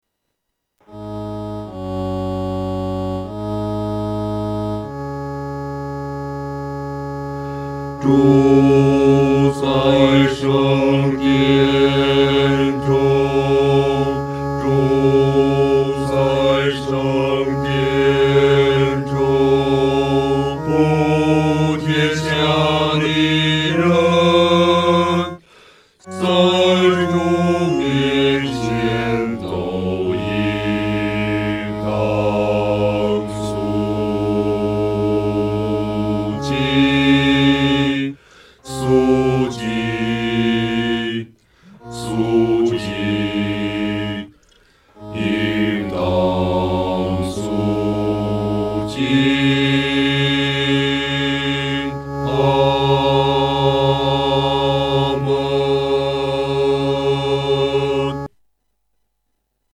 男高 下载